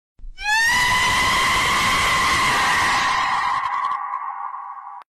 galinha gritando no telhado Meme Sound Effect
galinha gritando no telhado.mp3